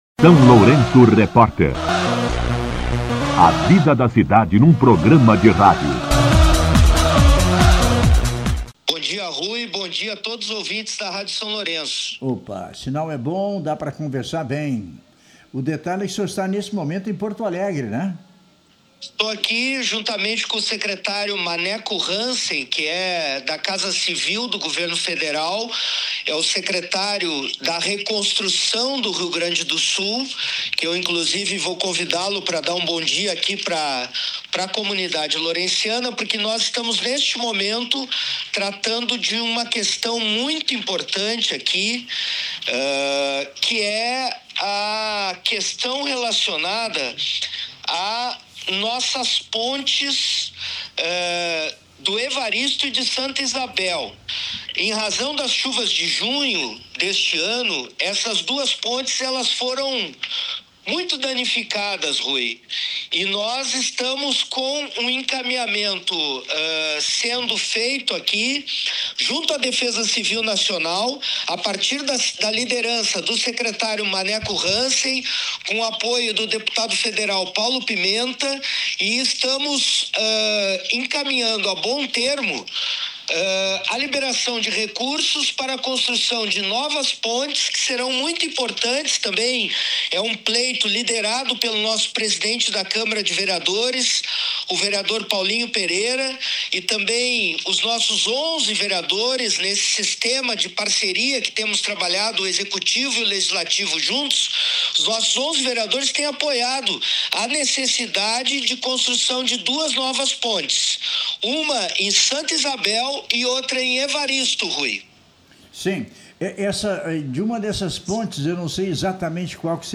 O prefeito Zelmute Marten conversou hoje (1º) com a SLR Rádio para detalhar sua importante agenda na capital gaúcha.
Entrevista com o prefeito Zelmute Marten